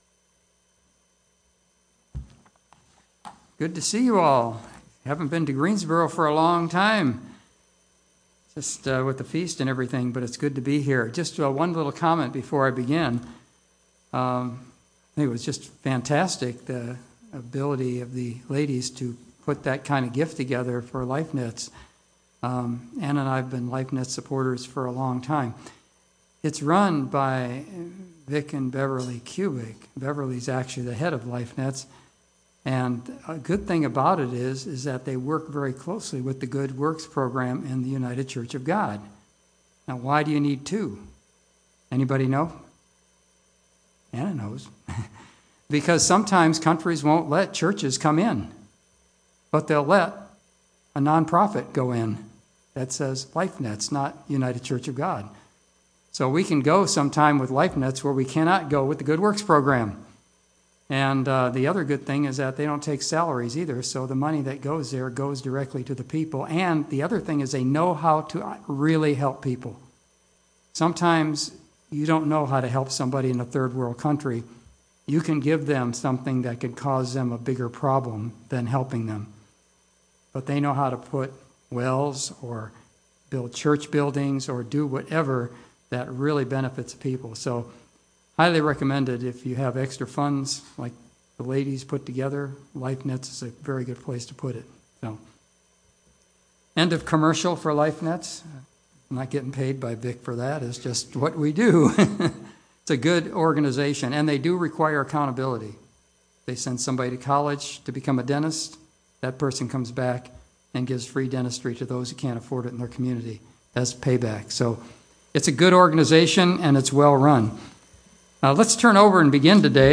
The Bible is the filter that can help us sort through the "noise" of the information that bombards us every day. In this sermon I proposed we start studying the Bible with the book of Proverbs since it addresses many of the questions that concern people today and can also be understood at all levels.